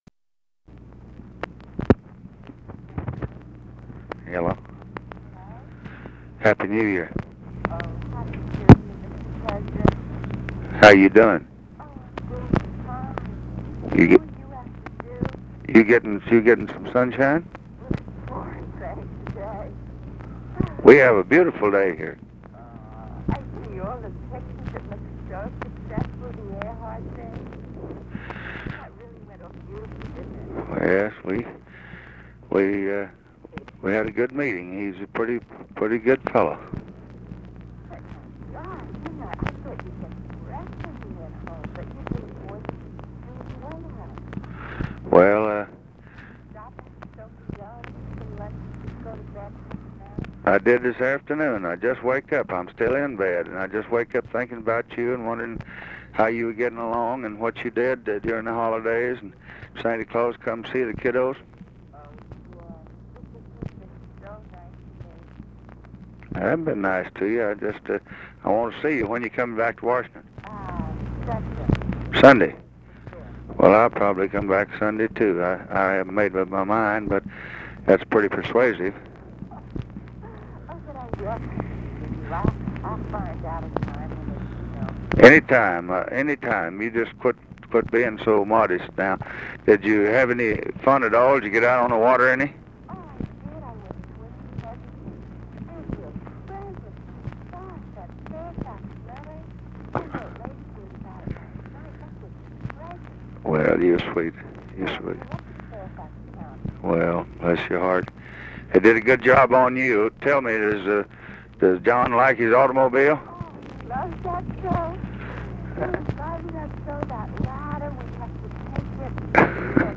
Conversation with JACQUELINE KENNEDY, January 1, 1964
Secret White House Tapes